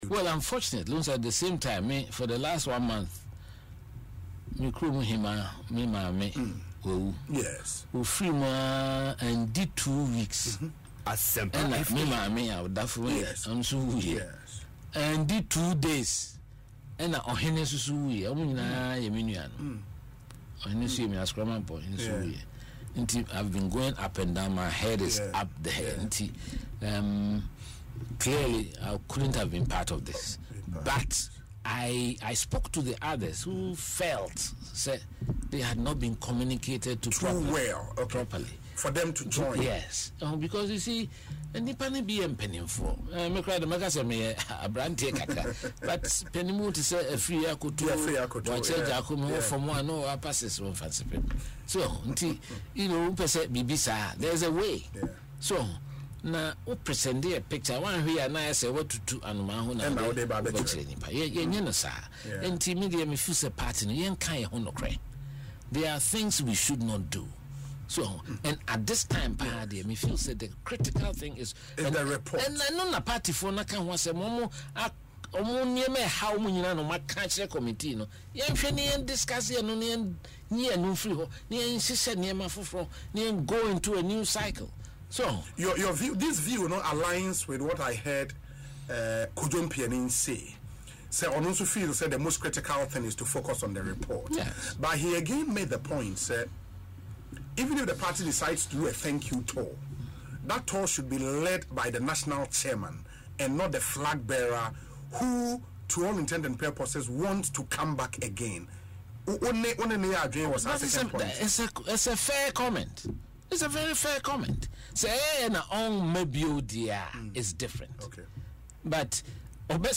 In an interview on Asempa FM Ekosii Sen, the former General Secretary emphasised that the NPP needs to focus on internal reflection and addressing the issues that contributed to its electoral loss.